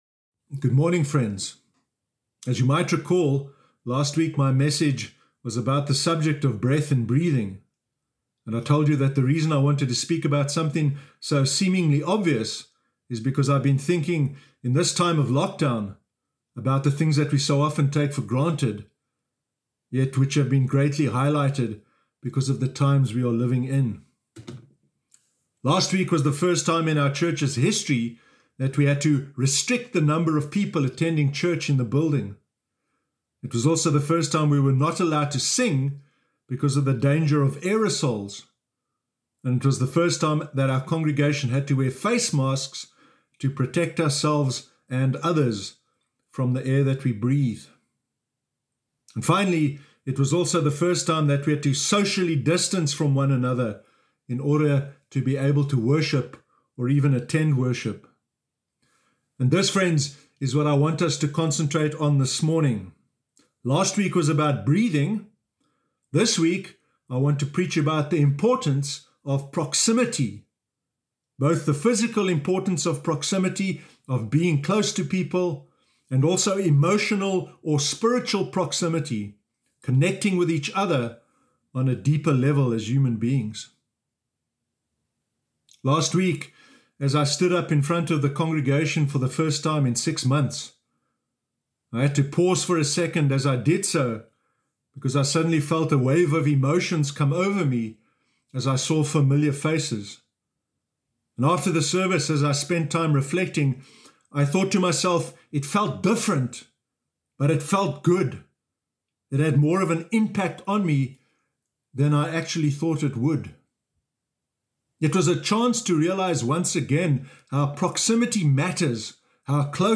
Sermon Sunday 27 September 2020